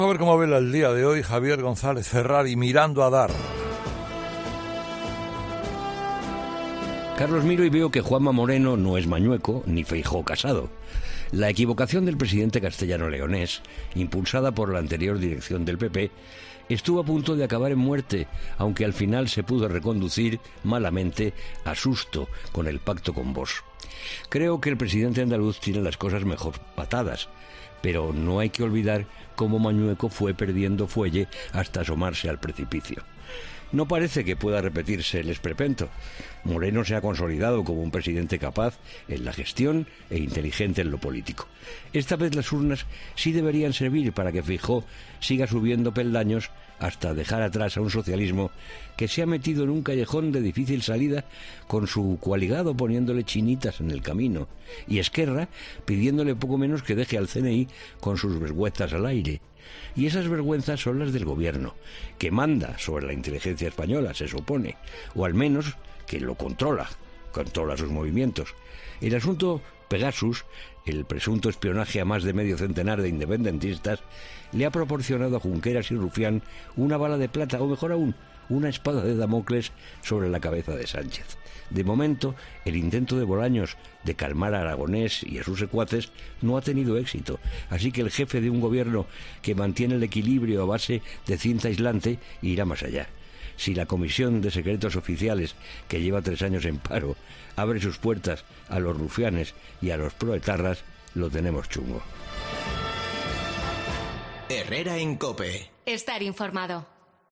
El periodista y colaborador de 'Herrera en COPE' ha analizado los peligros que pueden desestabilizar al presidente